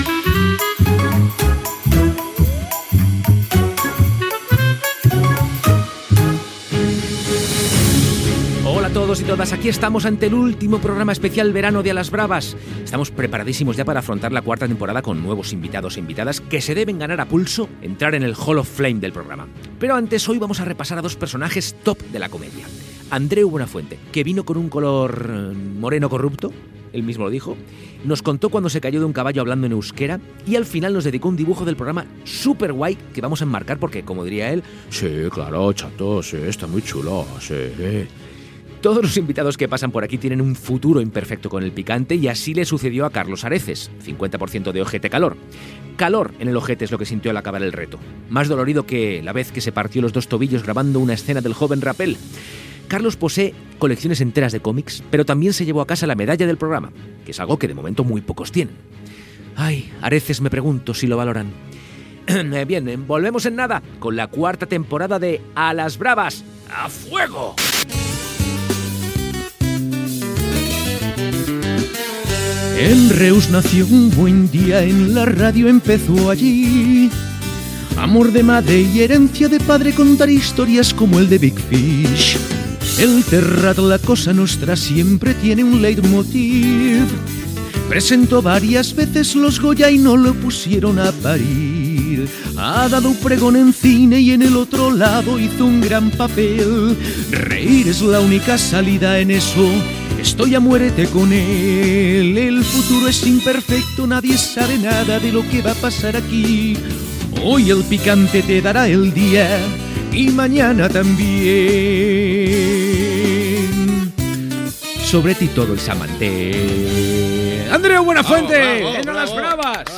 Reemissió d'un programa on el convidat és Andreu Buenafuente. Els convidats responen preguntes mentre van provant patates braves amb més o menys intensitat de picant.
Entreteniment